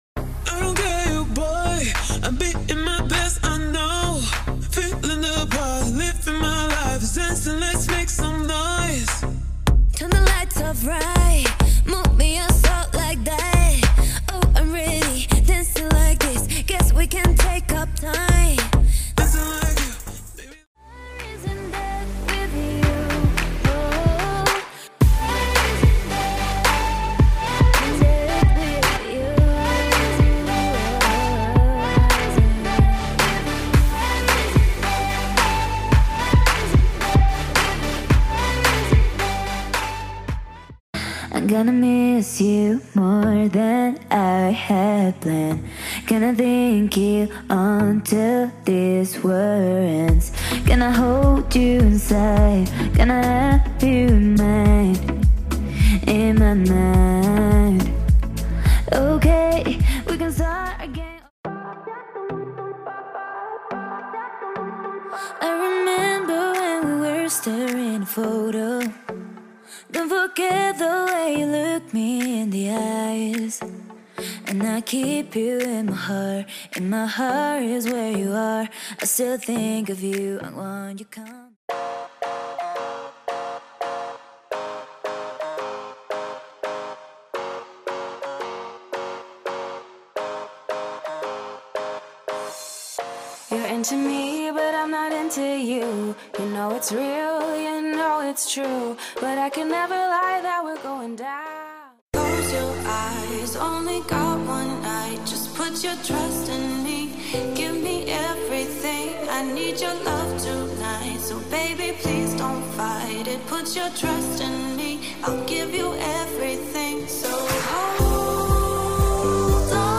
styl - pop/clubbing